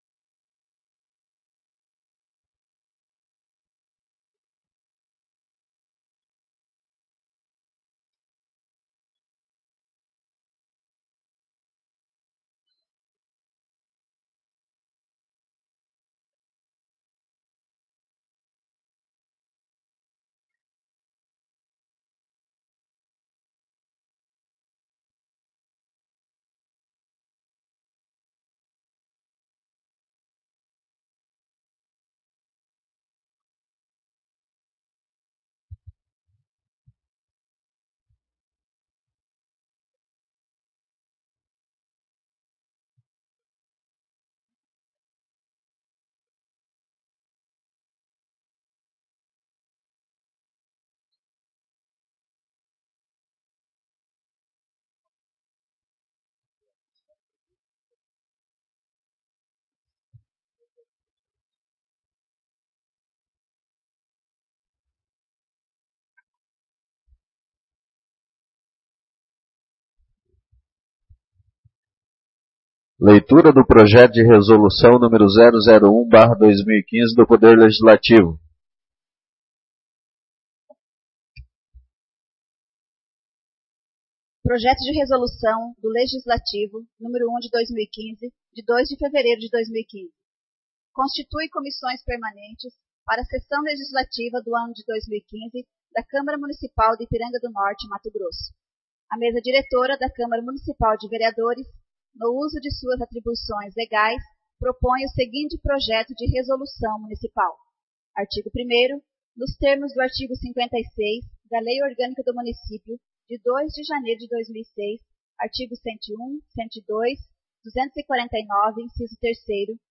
Primeira Sessão Ordinária 3/4